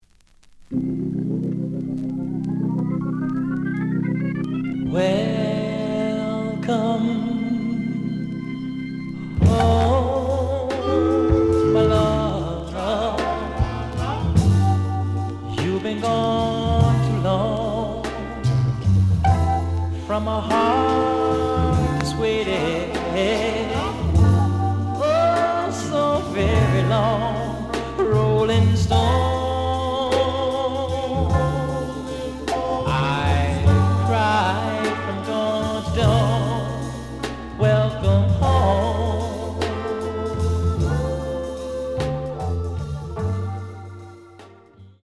The audio sample is recorded from the actual item.
●Genre: Soul, 70's Soul
Looks good, but slight noise on parts of both sides.